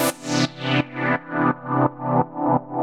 Index of /musicradar/sidechained-samples/170bpm
GnS_Pad-alesis1:4_170-C.wav